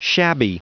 Prononciation du mot shabby en anglais (fichier audio)
Prononciation du mot : shabby